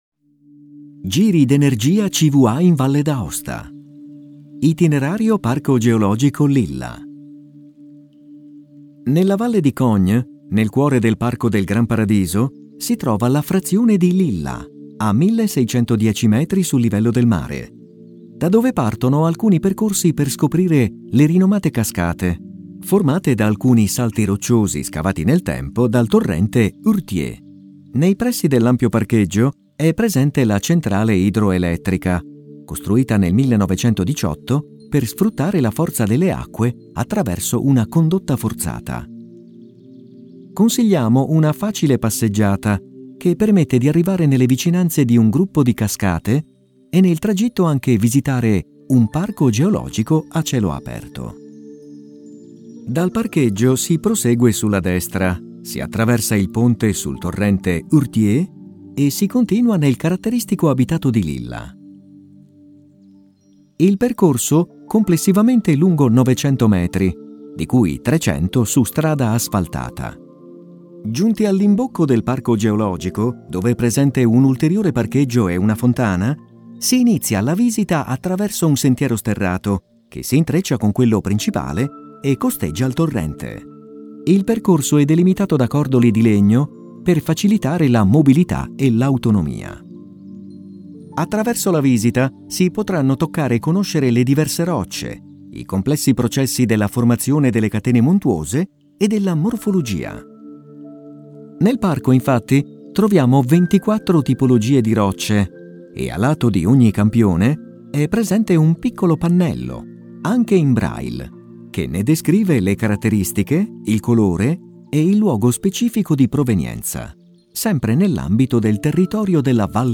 Audioguida Scheda bagno